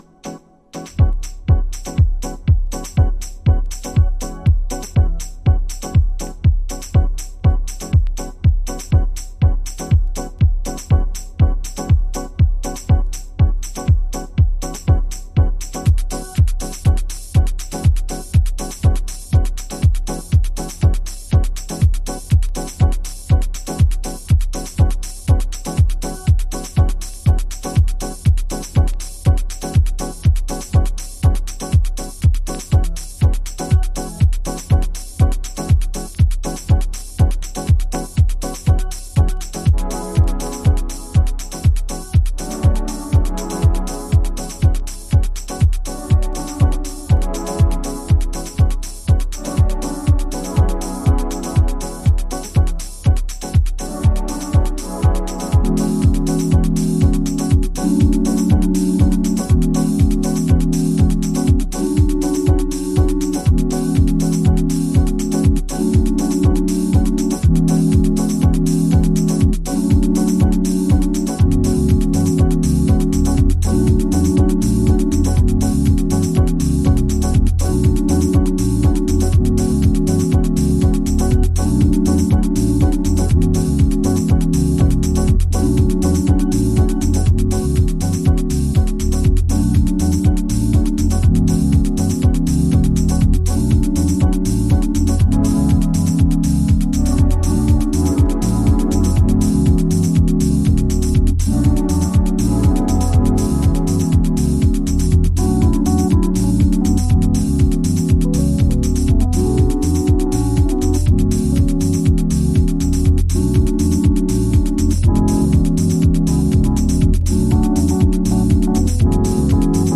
Detroit House / Techno